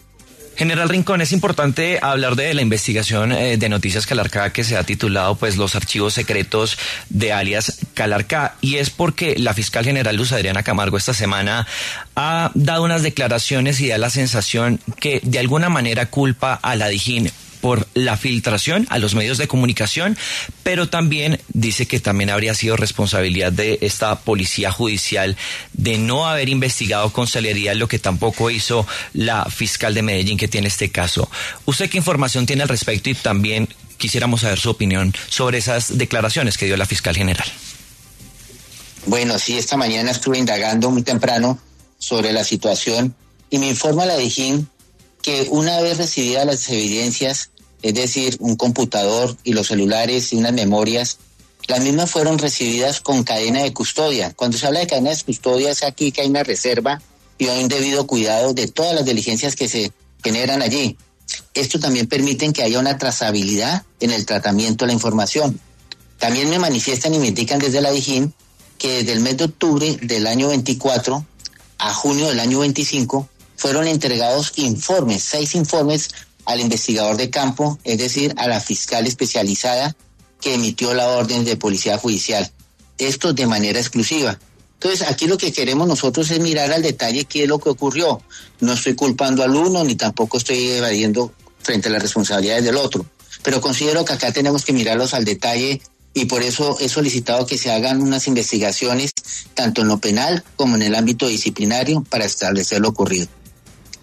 El director de la Policía Nacional, general William Rincón, se refirió en diálogo con La W a la reciente investigación revelada por Noticias Caracol, titulada ‘Los archivos secretos de alias Calarcá’, la cual abrió un nuevo capítulo en la tensión institucional entre la Fiscalía y la Dijín.